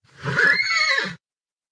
horse.mp3